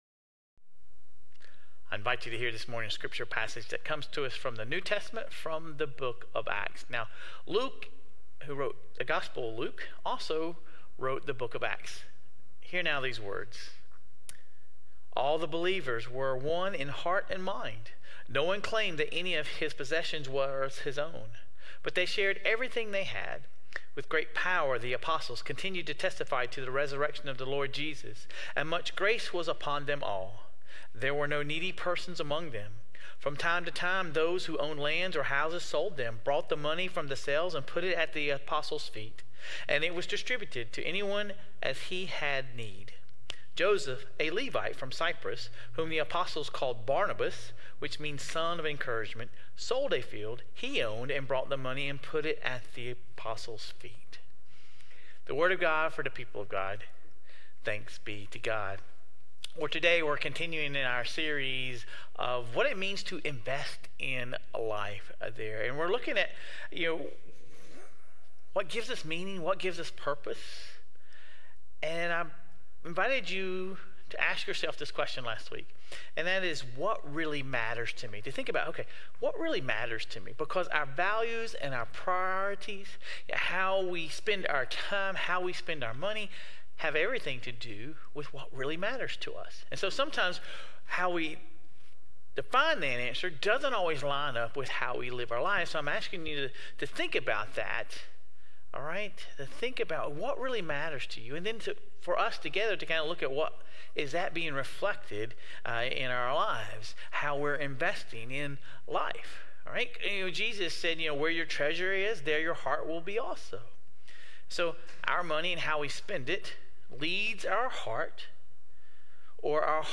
What lessons can we learn from Barnabas’ example of generosity? Sermon Reflections: What really matters to you?